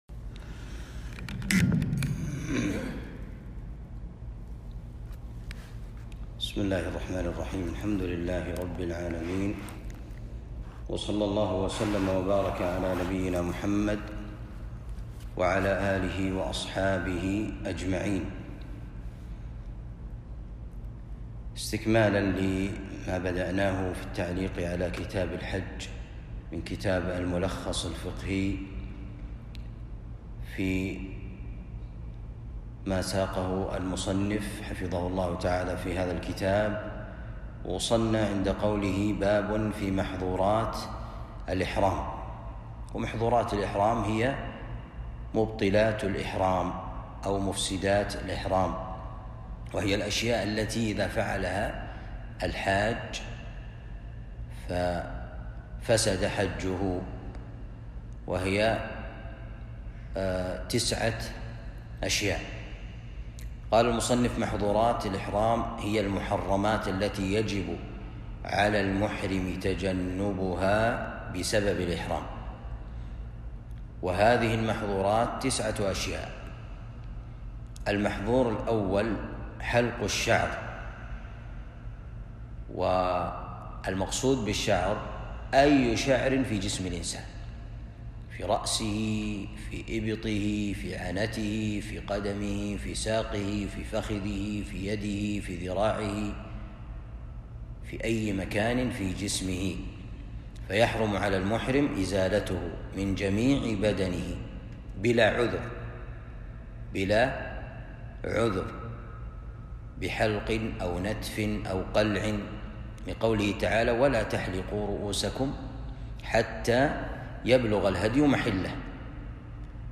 الدرس الخامس من كتاب الحج باب في محظورات الإحرام من كتاب الملخص الفقهي